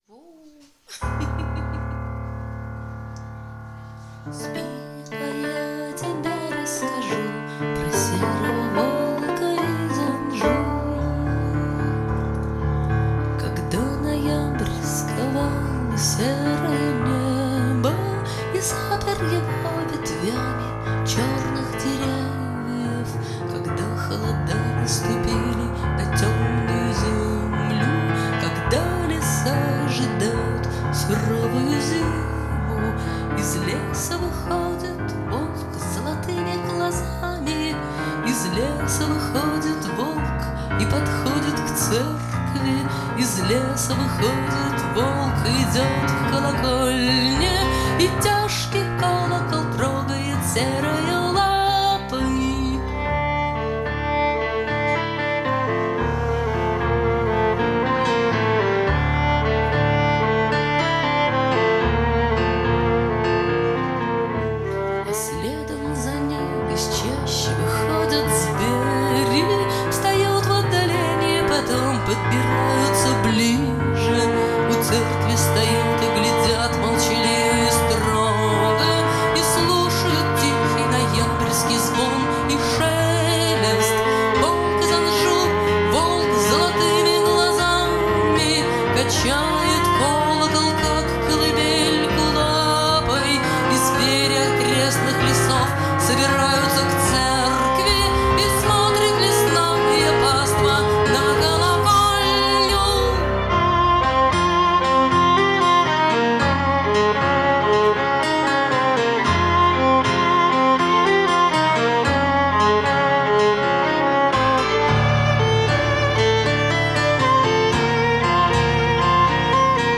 Качество, как всегда, не на высшем уровне, но это таки "Волк", мой нежно любимый "Волк из Анжу", и даже музыка именно такая, как я себе представляла.